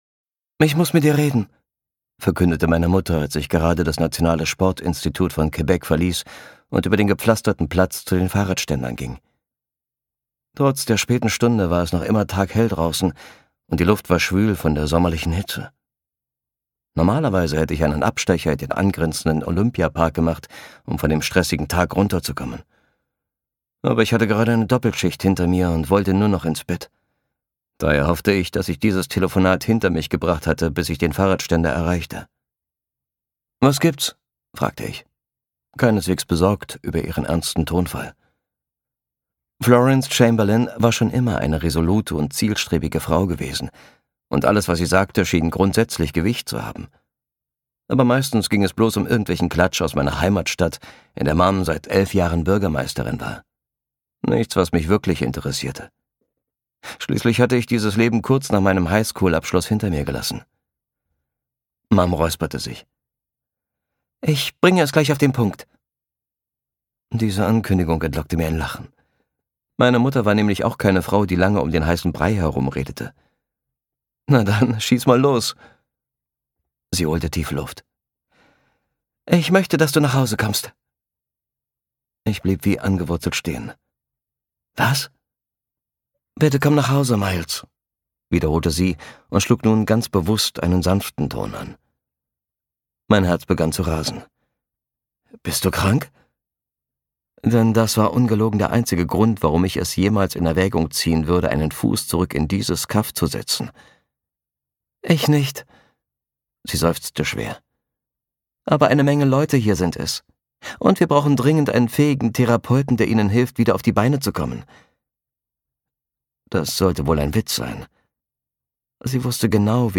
Knisternde New-Adult-Romance mit wunderschönem Herbst-Setting (Band 2)
Gekürzt Autorisierte, d.h. von Autor:innen und / oder Verlagen freigegebene, bearbeitete Fassung.